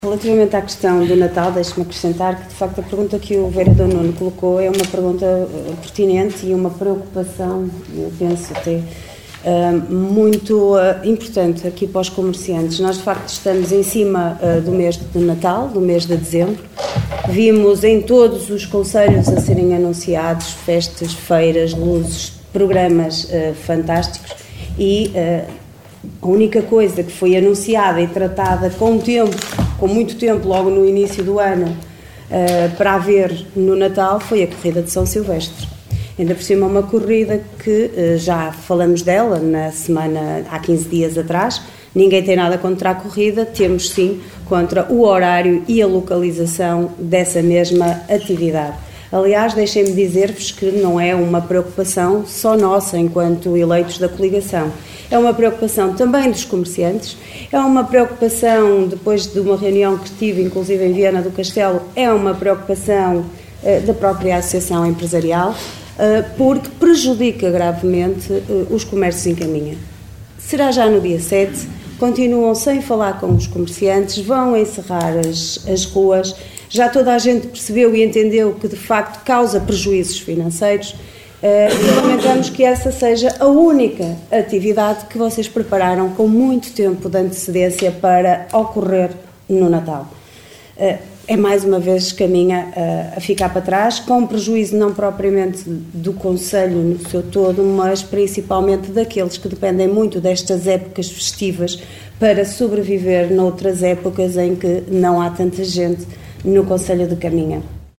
Excertos da reunião de Câmara realizada ontem à tarde no edifício dos Paços do Concelho.